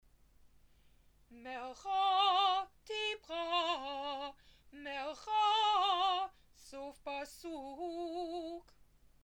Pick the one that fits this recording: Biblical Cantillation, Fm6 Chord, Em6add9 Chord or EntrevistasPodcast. Biblical Cantillation